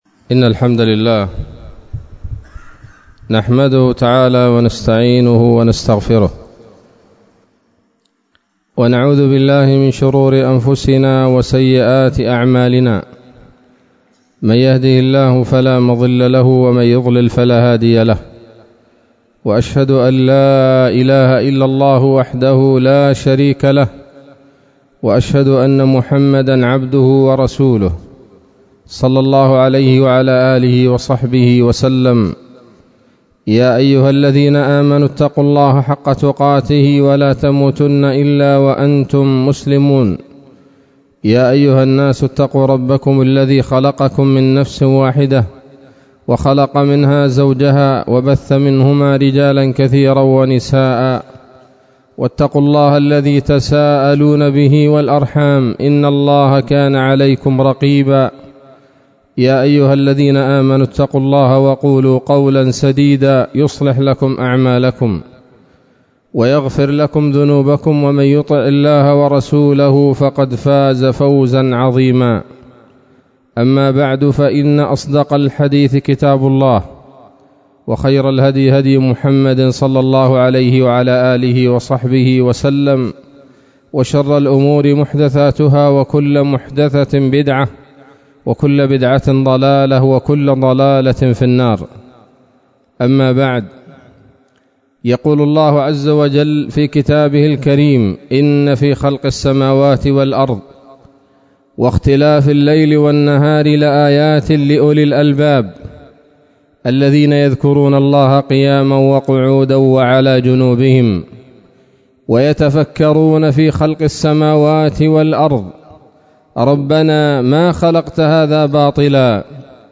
خطبة جمعة بعنوان: (( التفكر في آلاء الله )) 27 جمادى الأولى 1443 هـ، دار الحديث السلفية بصلاح الدين